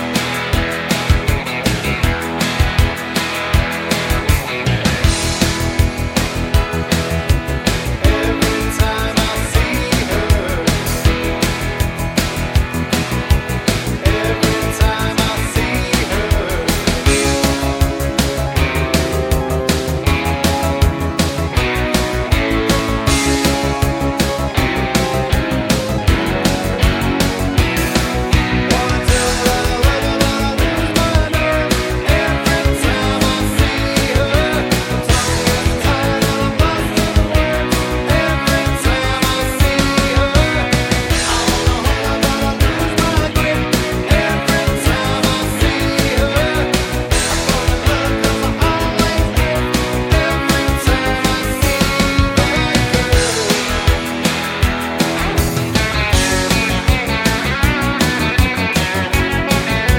no Backing Vocals Rock 'n' Roll 3:24 Buy £1.50